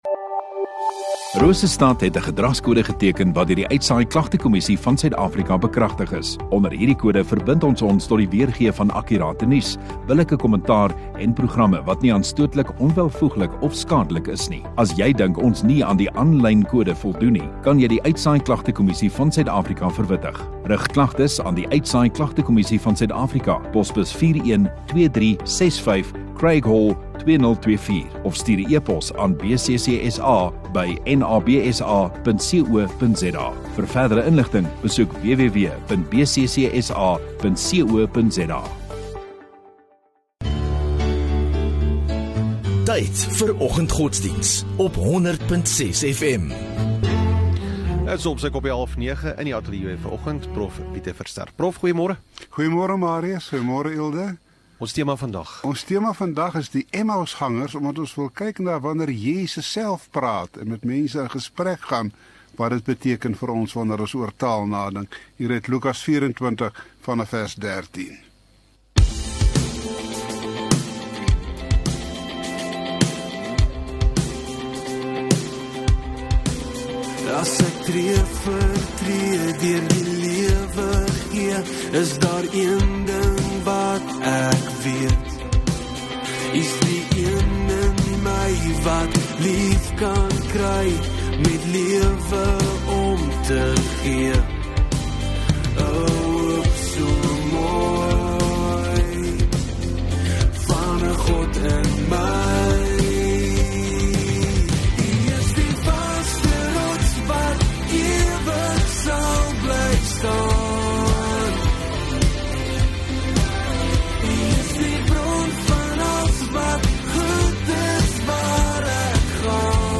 8 Aug Donderdag Oggenddiens